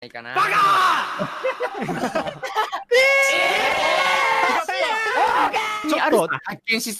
baka-laughs-ehhhh.mp3